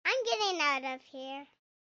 Babies and Toddlers by Big Room Sound
BRS_Voice_Girl_Toddler_I_m_Getting_Out_Of_Here